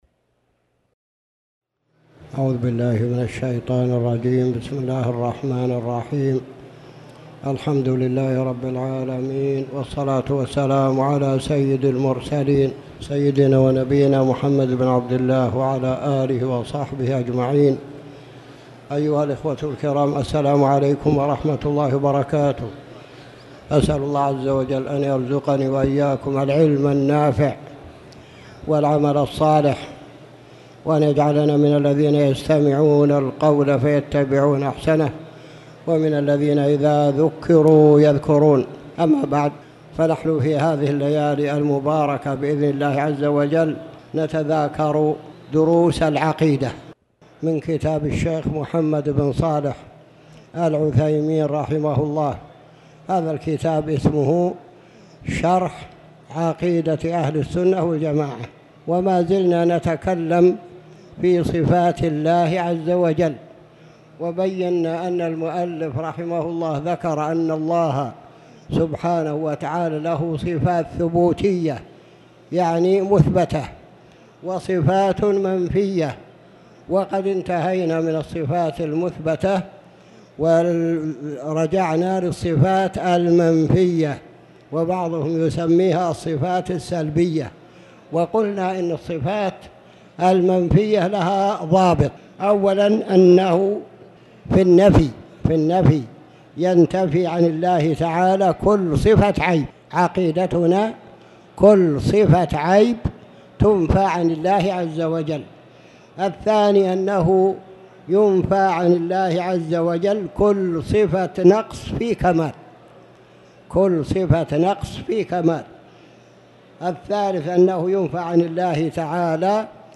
تاريخ النشر ١٦ صفر ١٤٣٩ هـ المكان: المسجد الحرام الشيخ